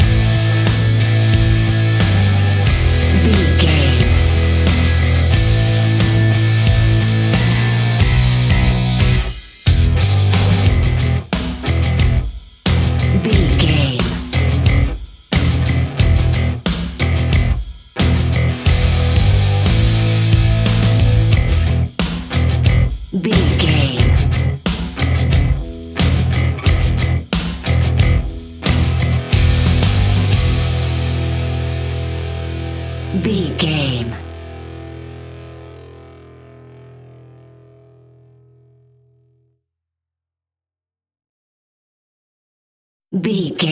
Aeolian/Minor
Slow
hard rock
heavy metal
Rock Bass
heavy drums
distorted guitars
hammond organ